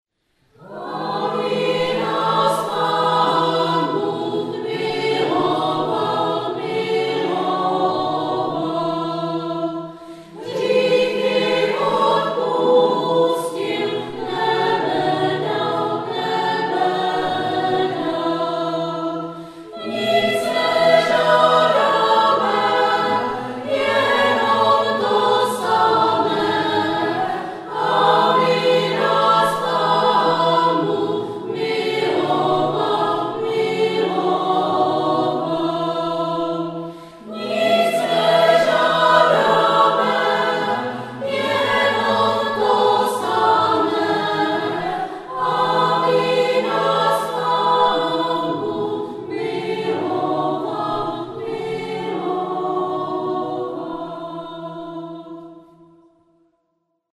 Živě z vánočního koncertu ze dne 19.12.2007